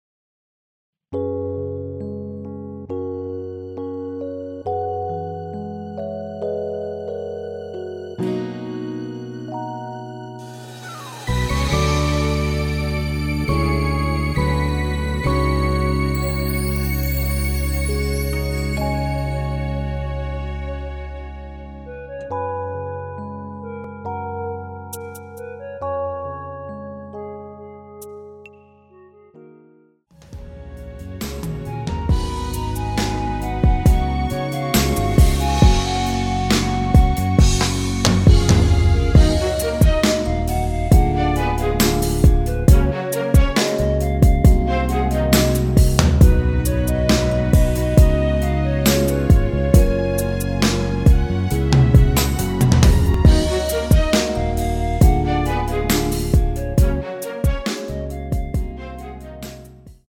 원키에서(-2)내린 (1절+후렴)으로 진행되는 멜로디 포함된 MR입니다.(미리듣기 확인)
Gb
앞부분30초, 뒷부분30초씩 편집해서 올려 드리고 있습니다.
중간에 음이 끈어지고 다시 나오는 이유는